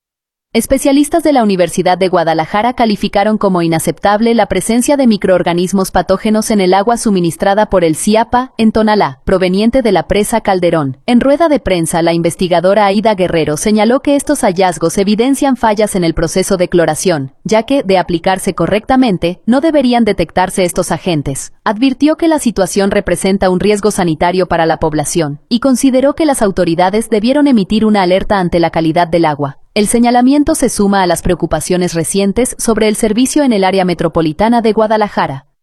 Especialistas de la Universidad de Guadalajara calificaron como inaceptable la presencia de microorganismos patógenos en el agua suministrada por el SIAPA en Tonalá, proveniente de la presa Calderón. En rueda de prensa